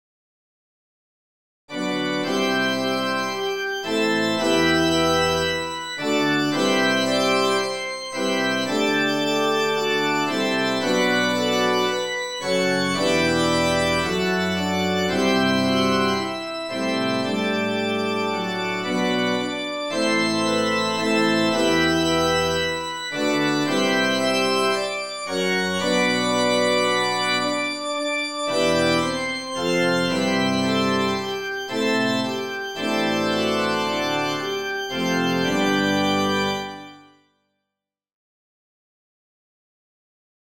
This bold free accompaniment on the restoration hymn
is perfect for unison singing.